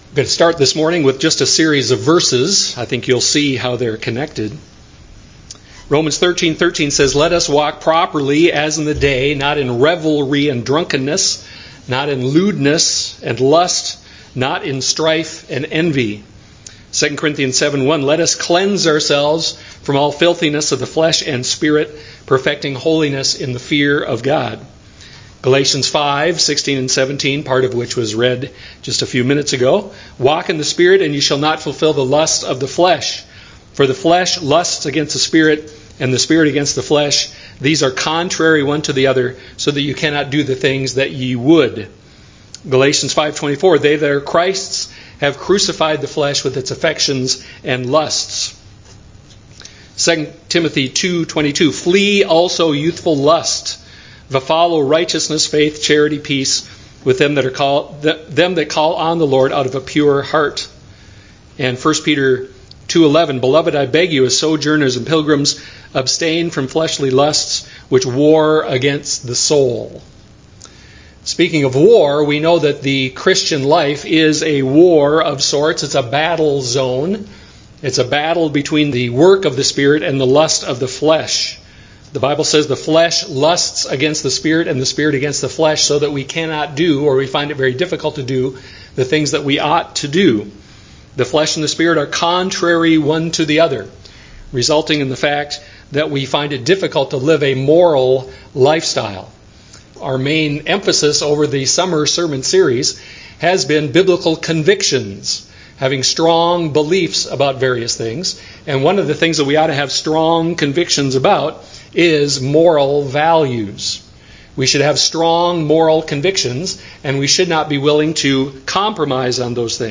Galatians 5:16-26 Service Type: Sunday morning worship service The Christian life is a battle zone.